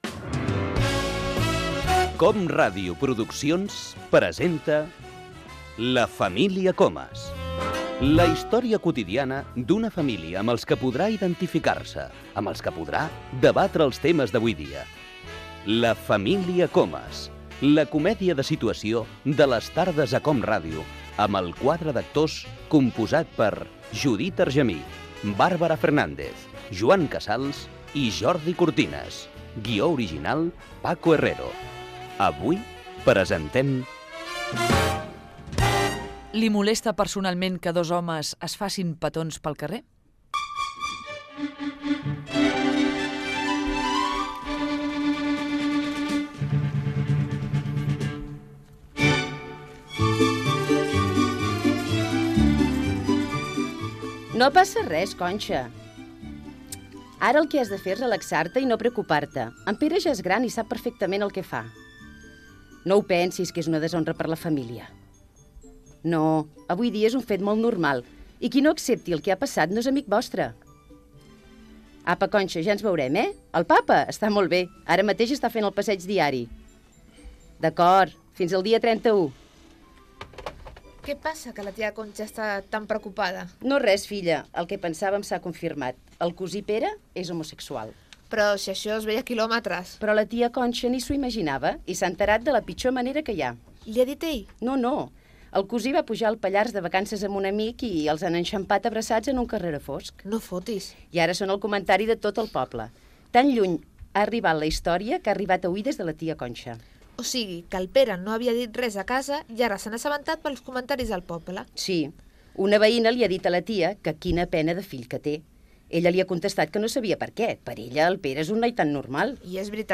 Secció "La família Comas", la història quotidiana d'una família. Careta de la secció. Capítol "Li molesta que els homes es facin petons al carrer".
Entreteniment